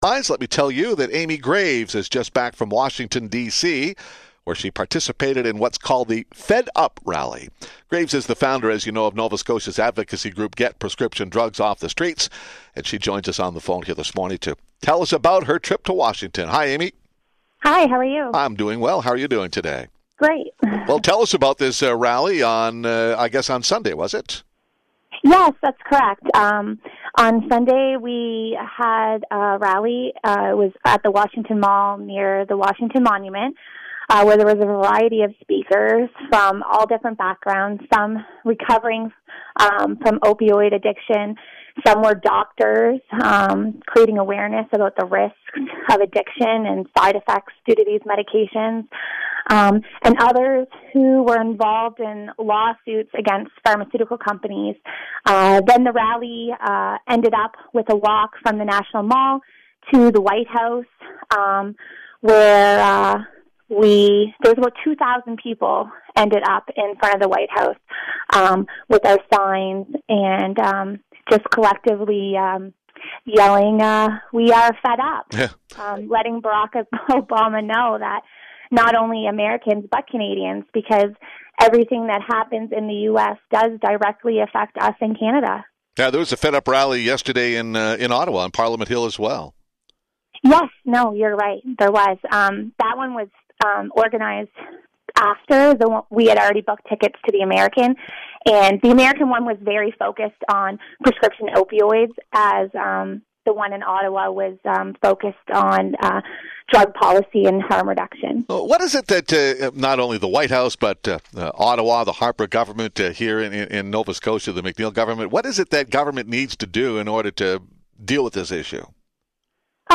Interview after the Fed Up Rally